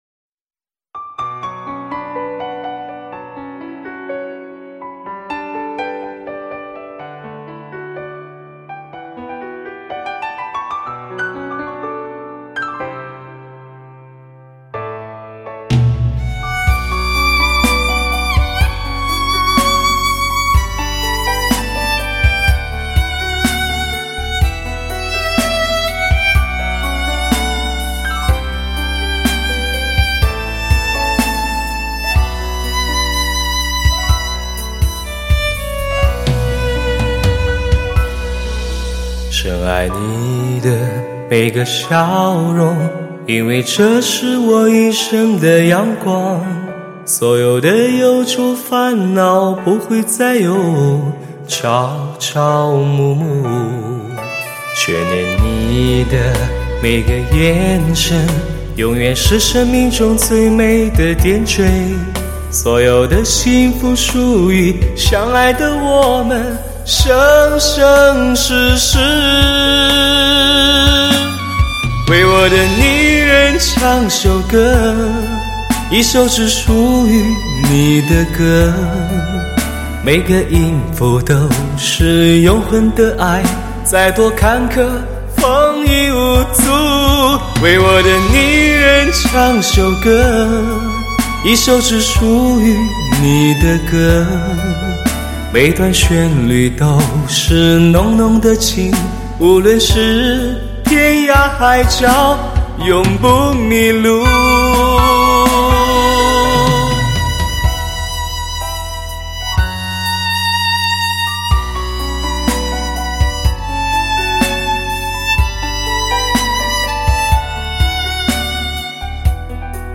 甄选倍受欢迎的流行金曲，云集最受欢迎的发烧男歌手汇聚最顶尖的制作人精心打造清晰、真实、无法挑剔的声音，
最佳演绎效果的流行发烧天碟